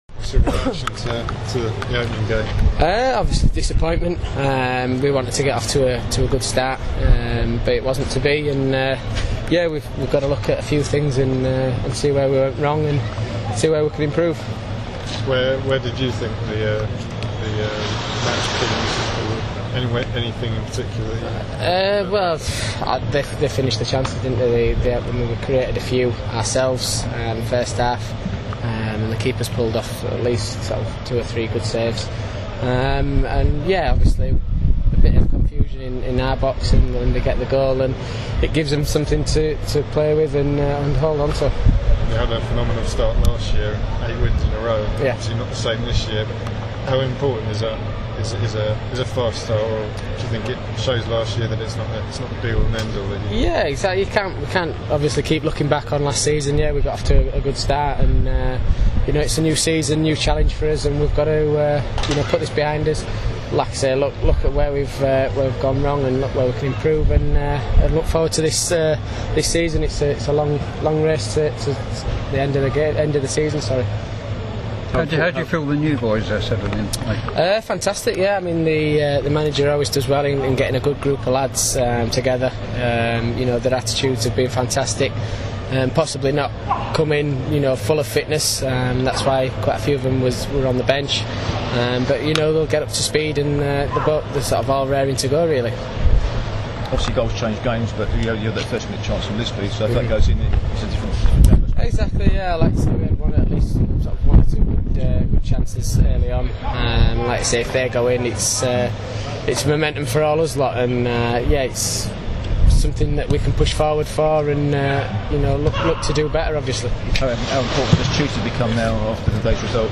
speaking after Orient vs Chesterfield